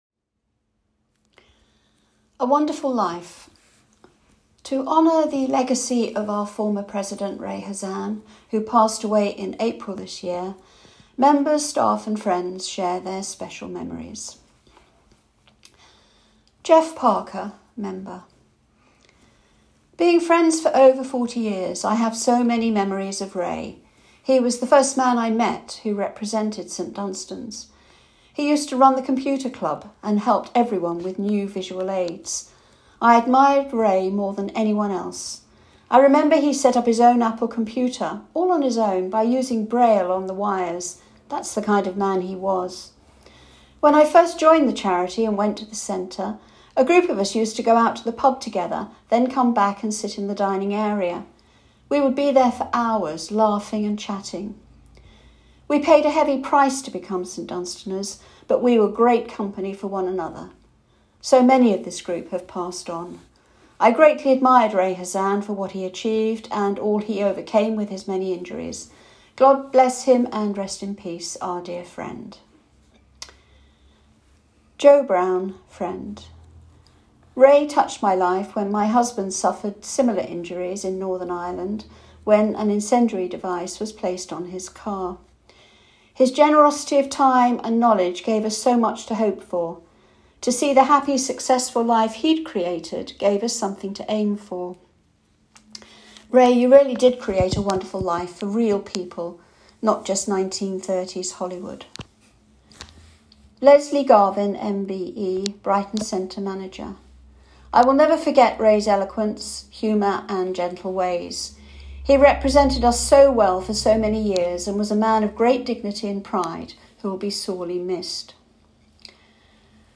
Members, staff and friends share their special memories